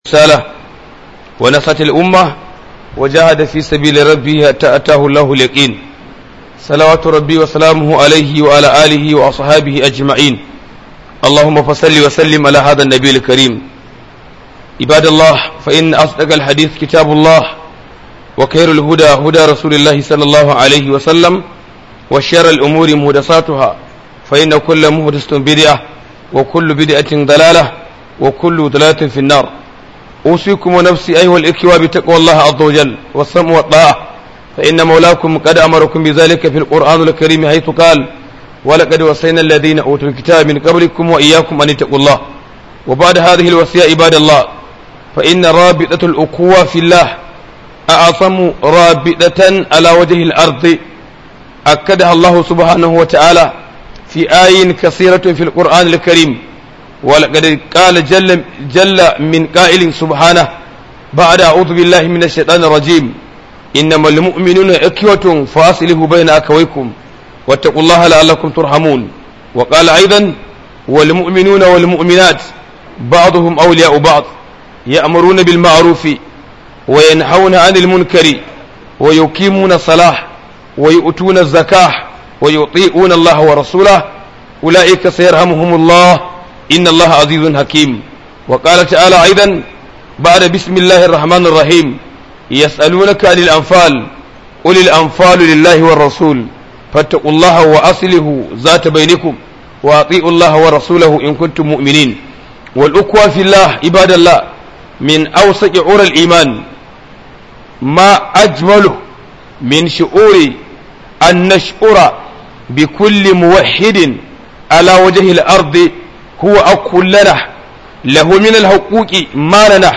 KHUDUBAN JUMMA'A